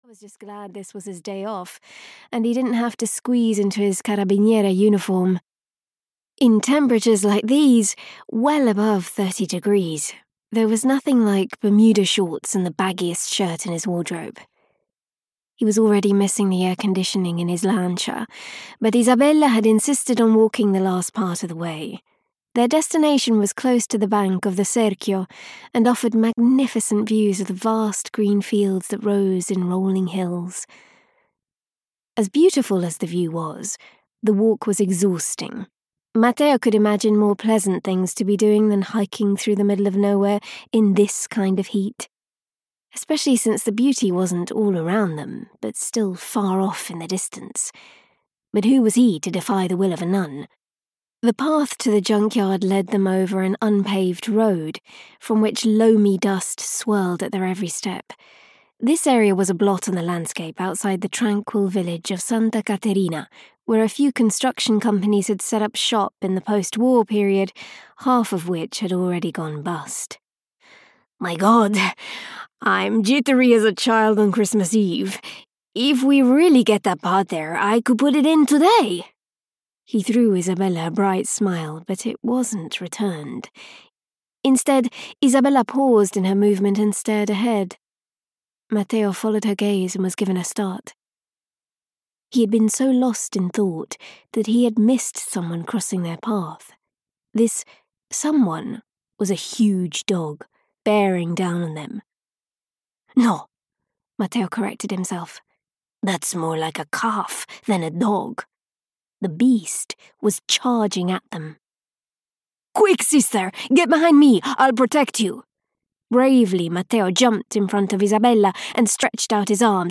Death by the River (EN) audiokniha
Ukázka z knihy